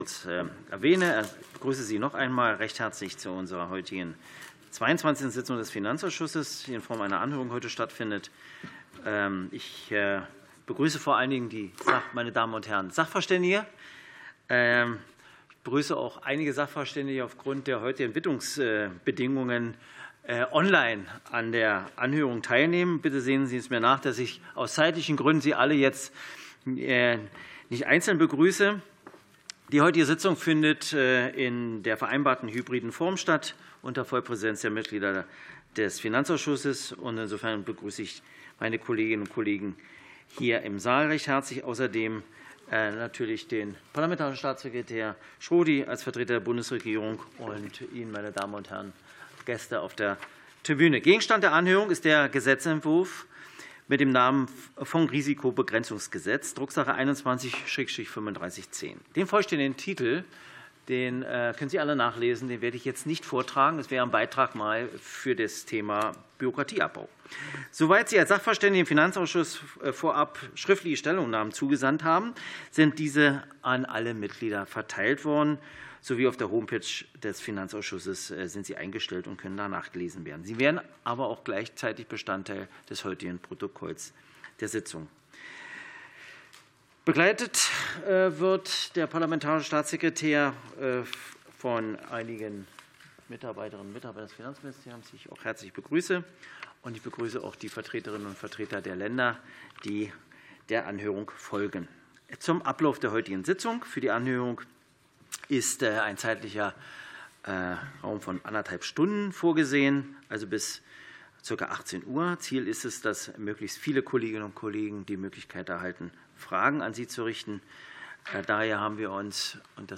Anhörung des Finanzausschusses